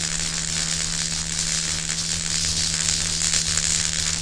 Index of /sound/ambient/energy/
electric_loop.mp3